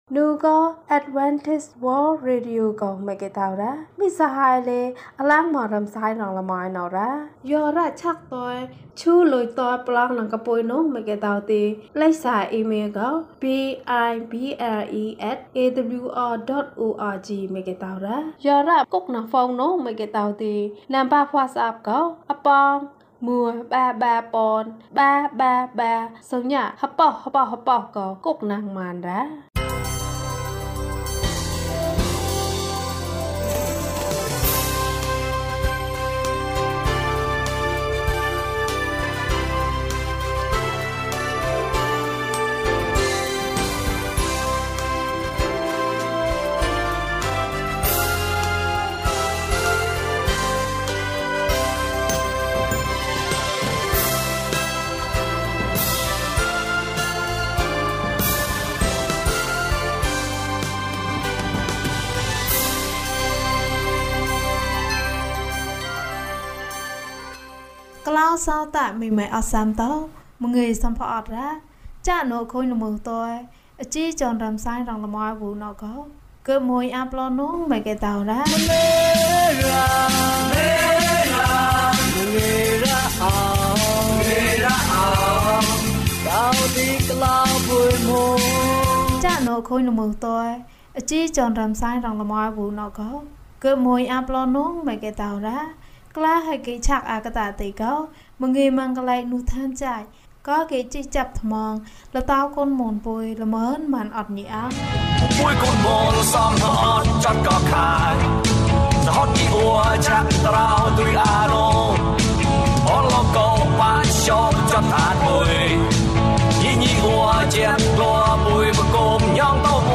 ယေရှုခရစ်၏အသက်တာ။၀၂ ကျန်းမာခြင်းအကြောင်းအရာ။ ဓမ္မသီချင်း။ တရားဒေသနာ။